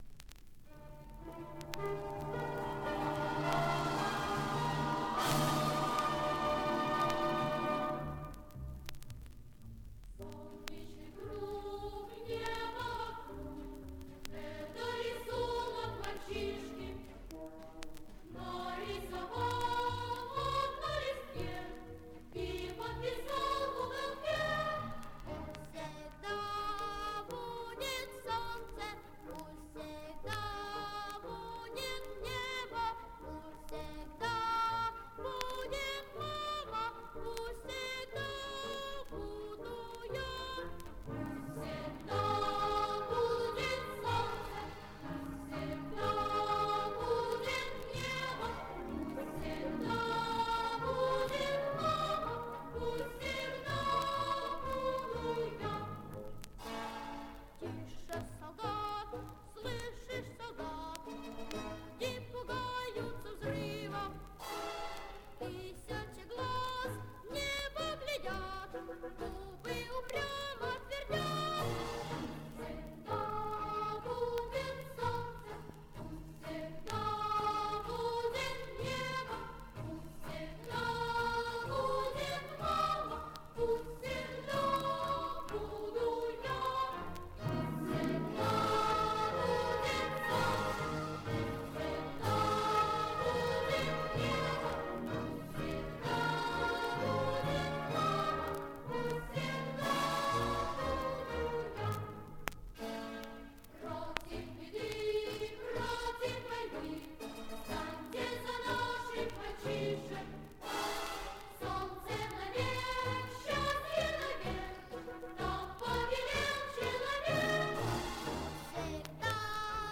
в исполнении детского хора